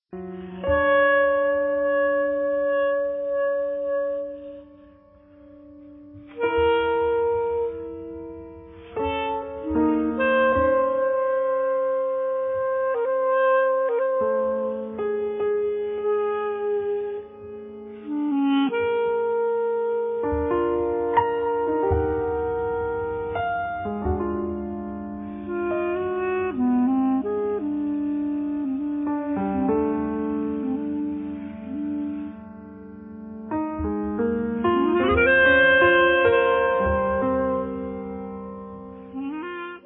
alto sax, clarinetto, clarinetto basso
piano, tastiere, laptop
contrabbasso
batteria, vari oggetti
I temi sono spigolosi, pungenti